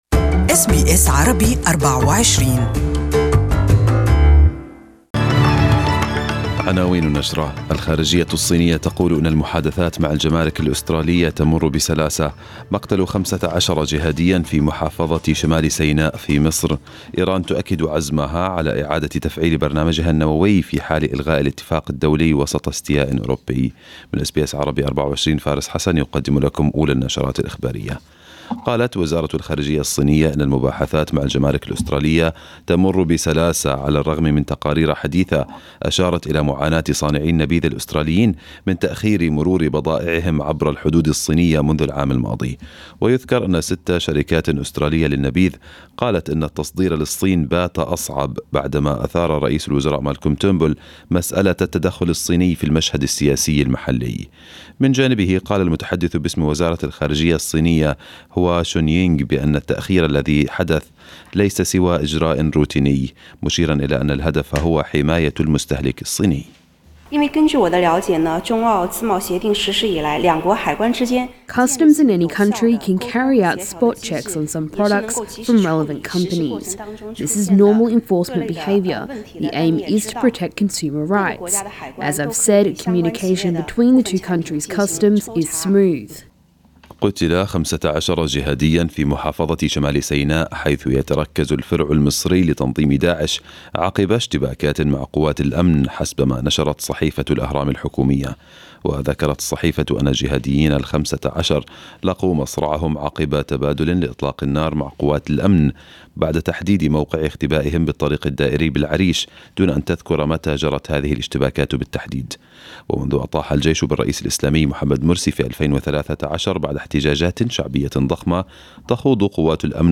Arabic News Bulletin 07/06/2018